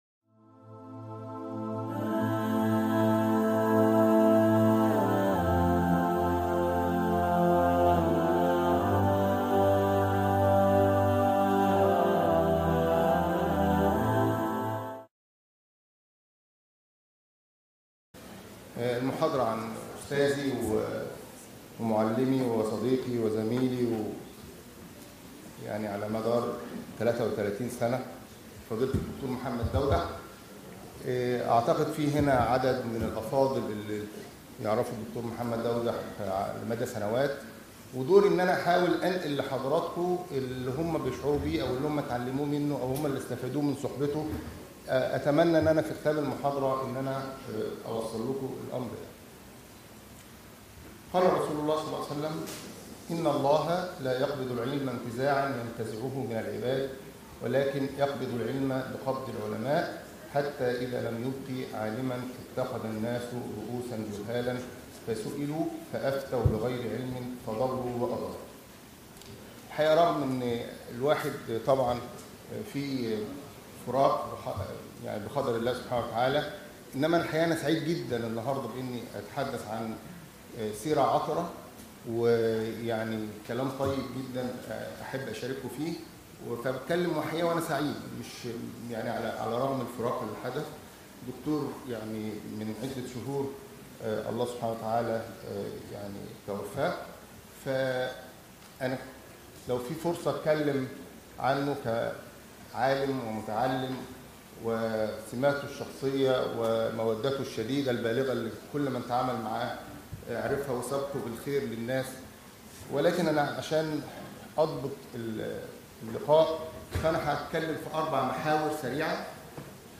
كلمة
بالمؤتمر السنوى العاشر للإعجاز العلمى بجامعة المنصورة 2018